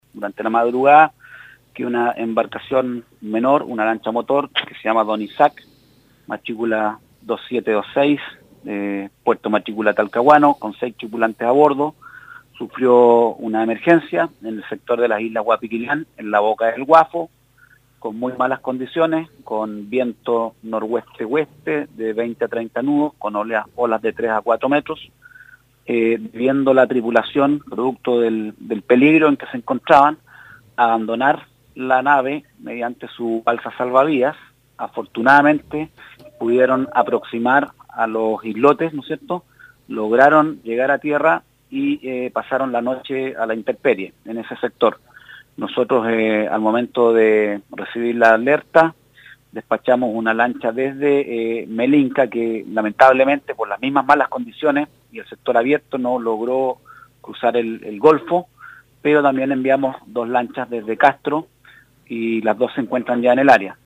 El Gobernador Marítimo de Castro, Javier Mardones explicó cómo se desarrolló la búsqueda y rescate de los tripulantes.